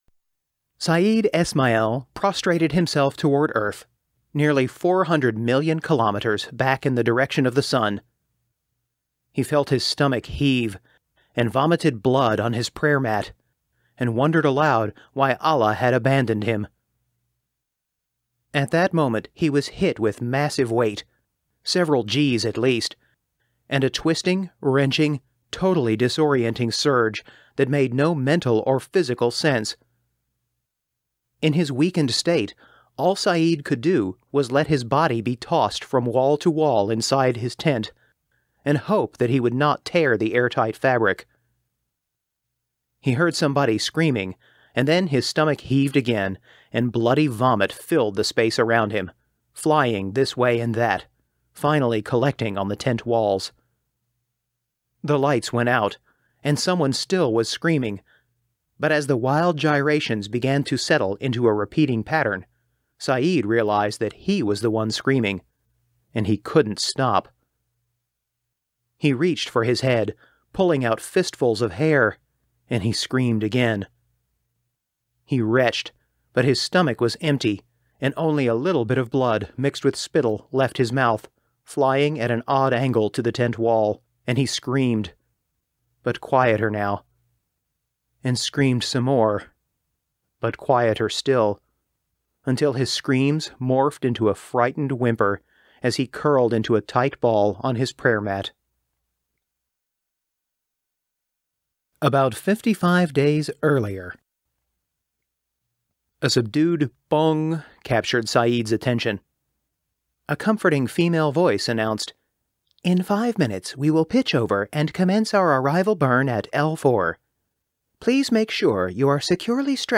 Audiobook (Bridgebreaker):Audiobook (Starchild Compact):
US: General American, Kentucky, Texas